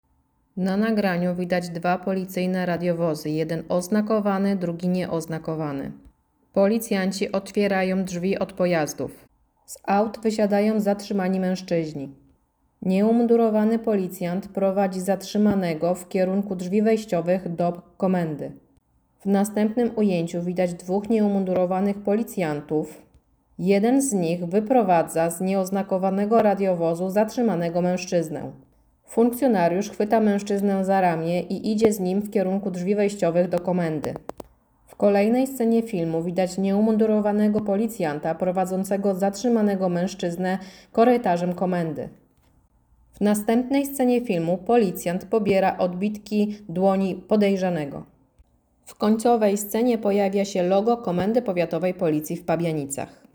Nagranie audio Audiodeskrypcja nagrania